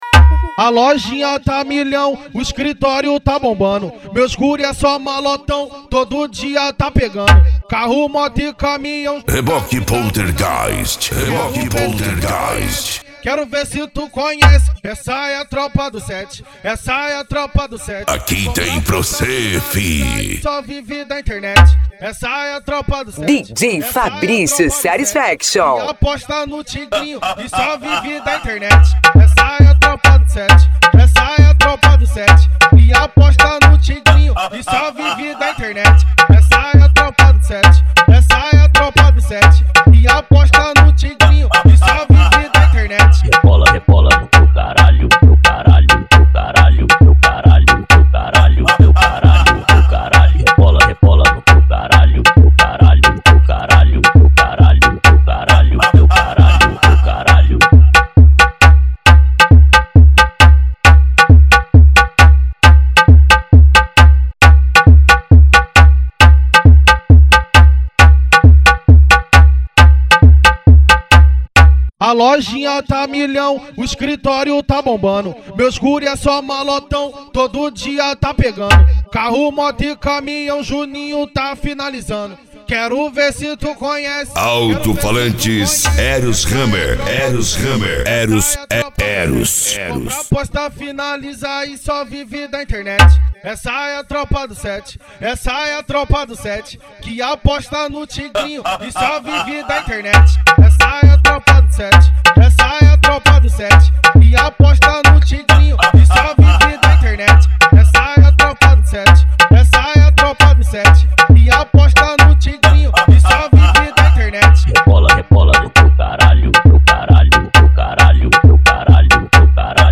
Funk